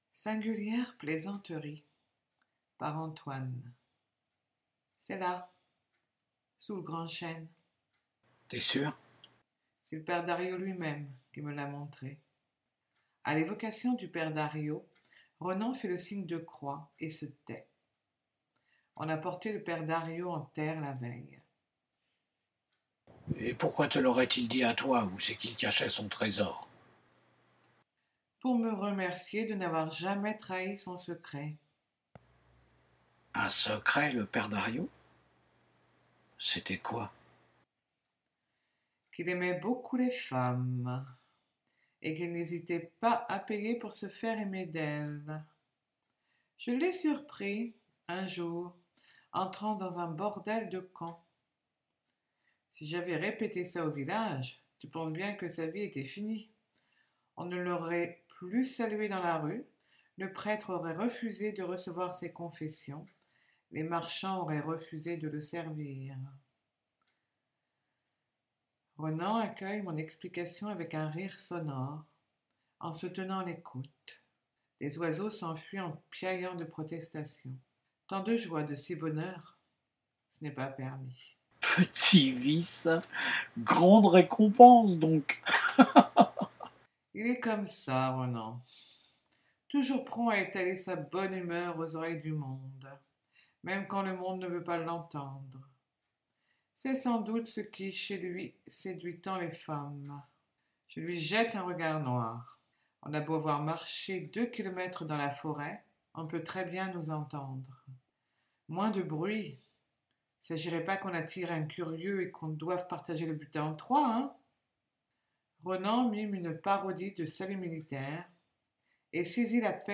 Moi aussi je suis fan des lectures à haute voix…définitivement…